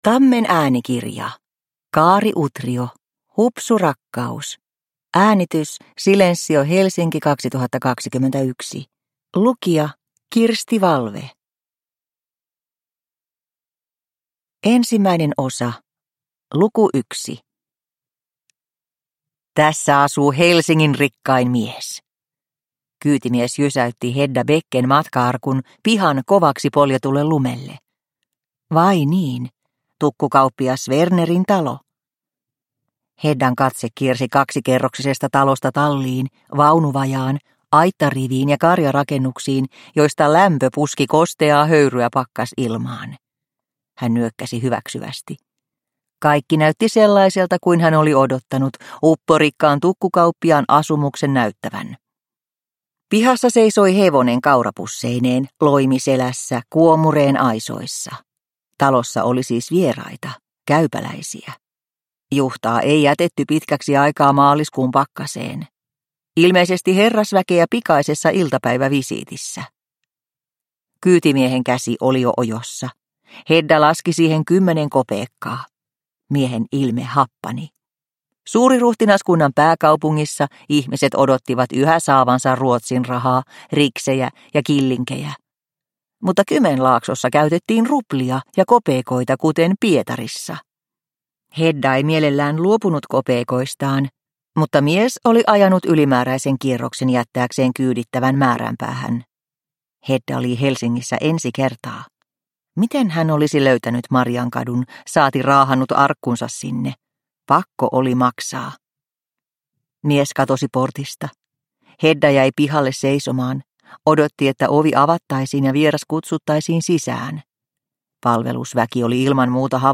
Hupsu rakkaus (ljudbok) av Kaari Utrio